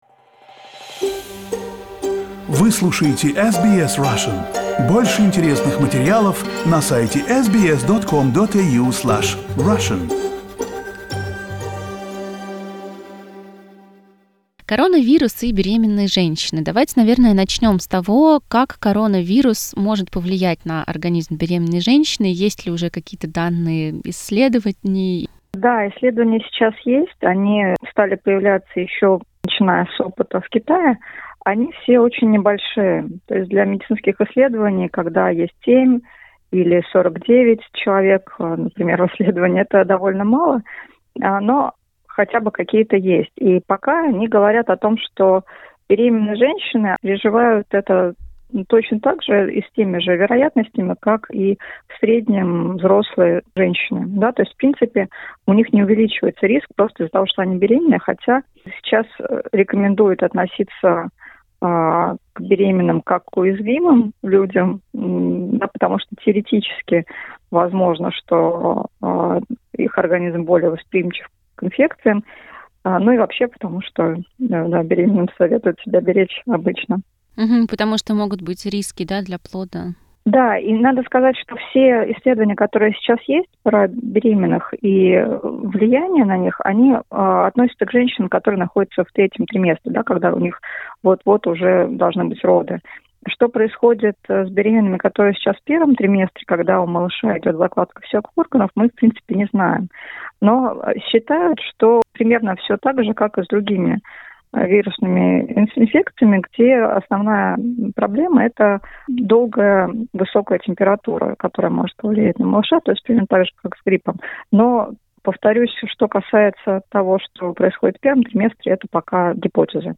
Delivery during coronavirus crisis: interview with a doula